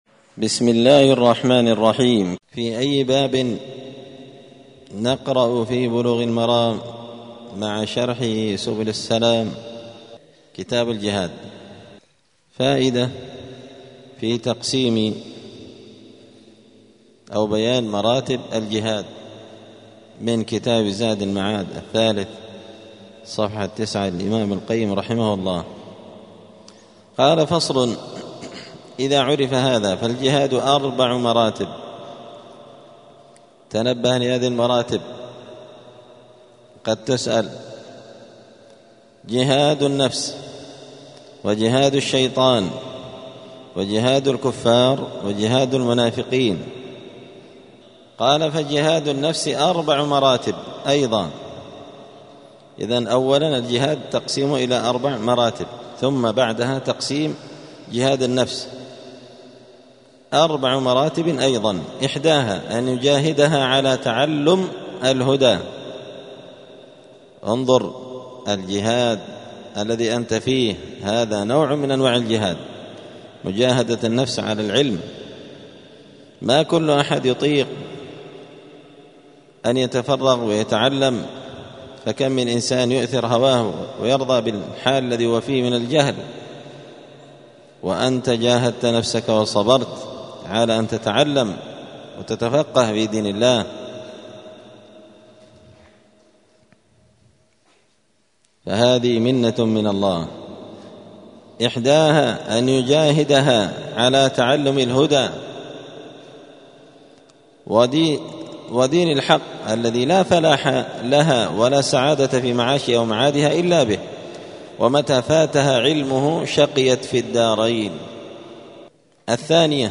*الدرس الثاني (2) {باب بيان مراتب الجهاد}*
دار الحديث السلفية بمسجد الفرقان قشن المهرة اليمن